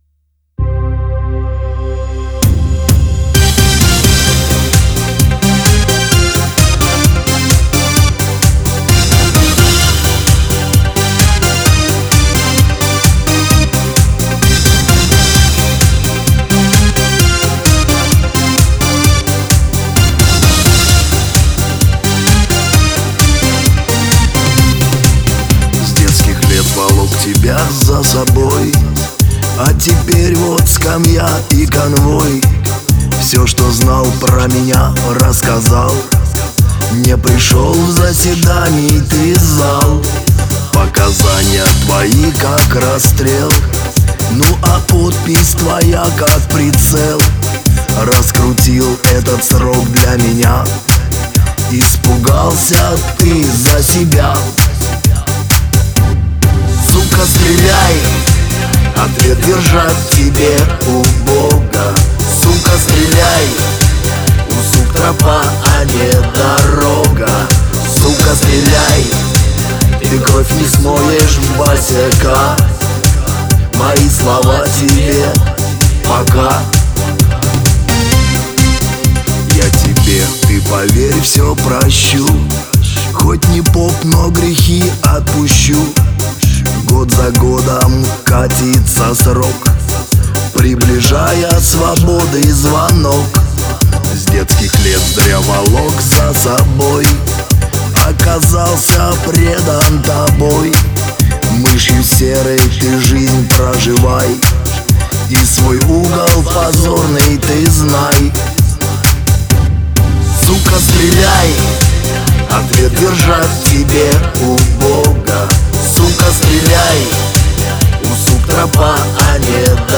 Жанр: Шансон Формат